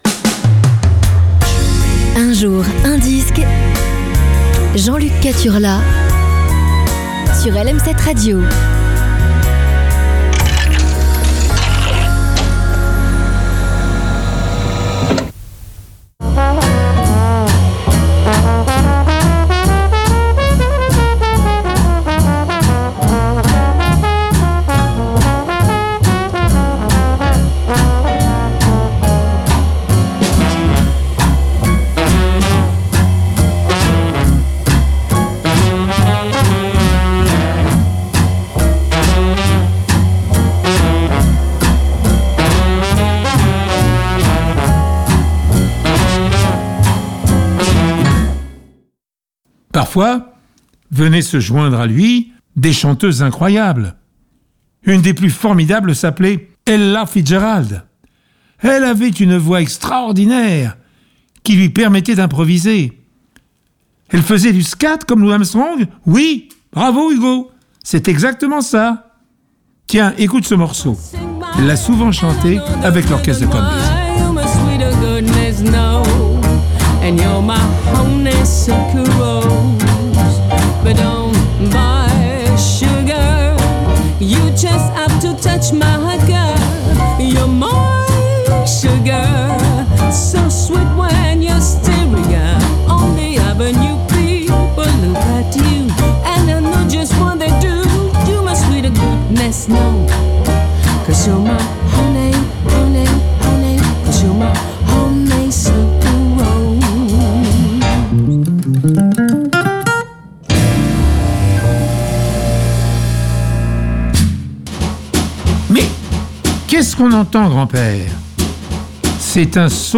racontée par Pierre Bellemare